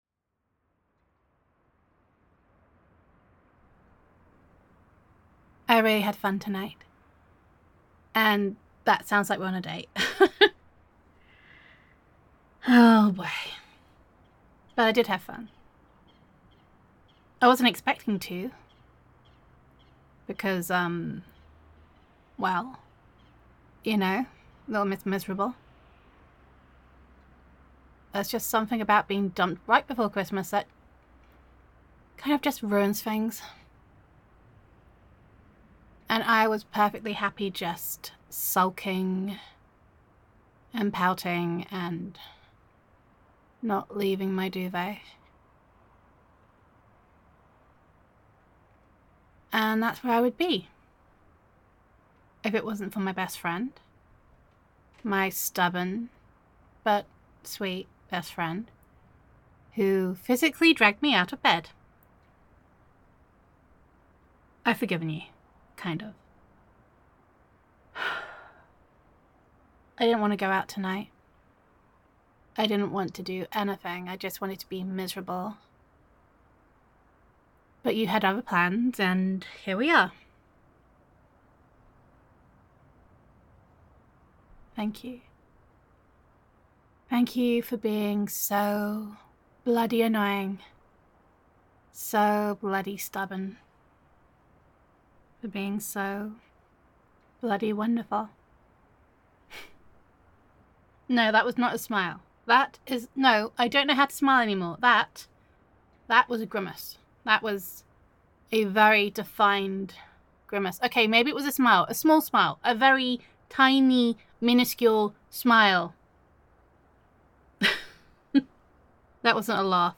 [F4A] Sleigh My Name, Sleigh My Name [Friends to Lovers][Love Confession][Reverse Comfort][Being Dumped][Best Friend Roleplay][First Kiss][Jealousy][Grumpy Honey][Gender Neutral][Taking Your Best Friend Out After She Is Dumped Right Before Christmas]